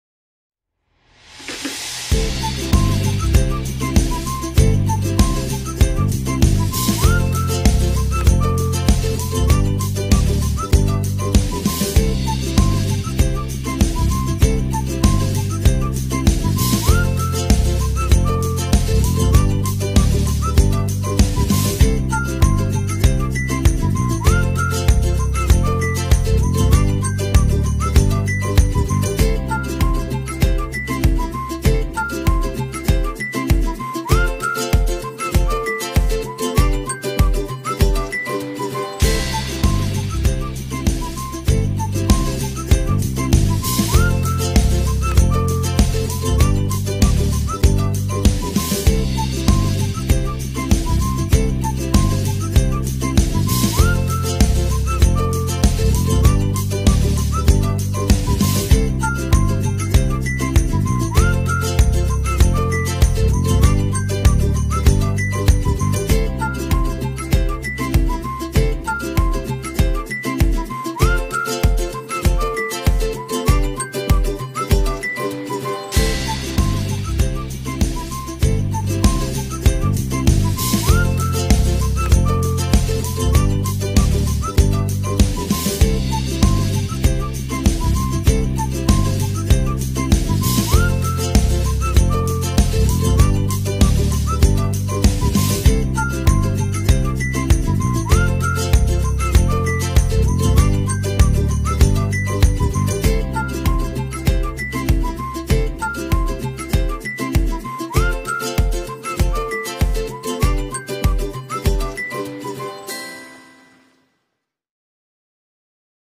tema dizi müziği, mutlu huzurlu rahatlatıcı fon müziği.